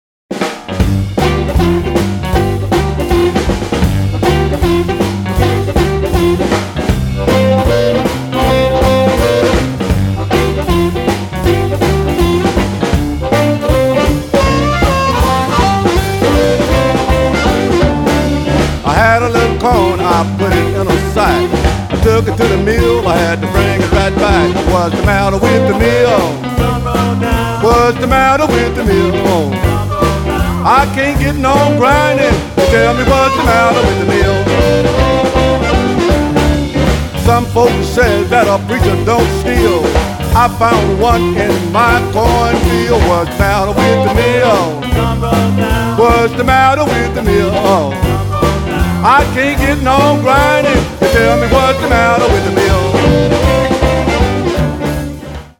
traditional blues